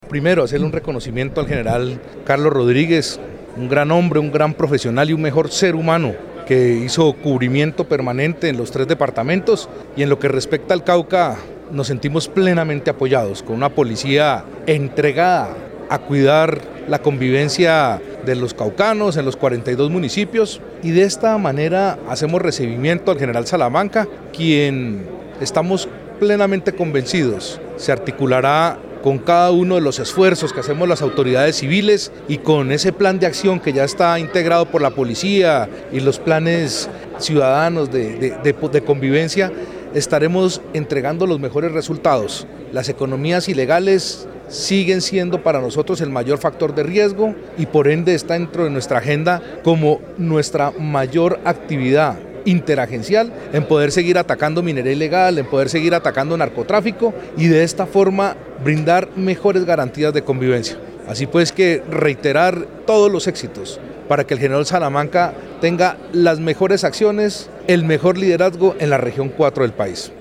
Durante el acto protocolario de Transmisión de mando,  el mandatario de los caucanos reafirmó su compromiso con las acciones adelantadas por la Policía Nacional a fin de mejorar los {índices de convivencia  y seguridad en el departamento.
FULL_OSCAR-RODRIGO-CAMPO-HURTADO_GOBERNADOR-DEL-CAUCA-1.mp3